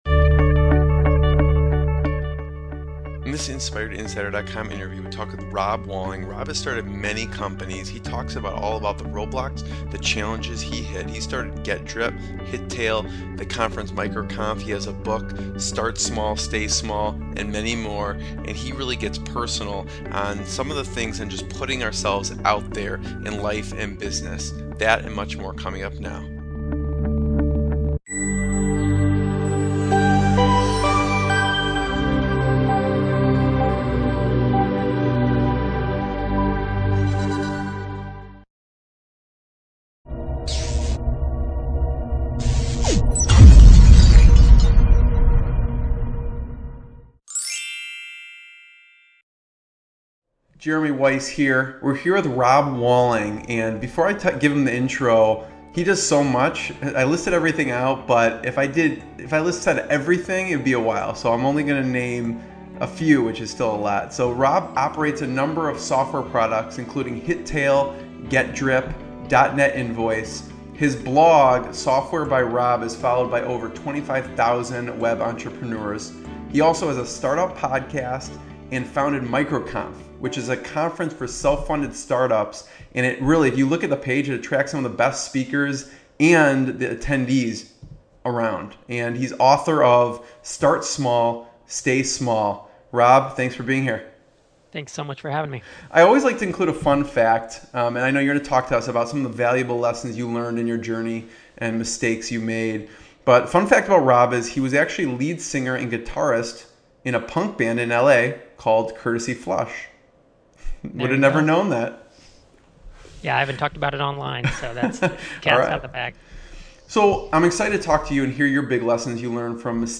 Watch this interview: [Video & mp3 included] -What were his failures before success? -How did he overcome putting himself out there when there are naysayers?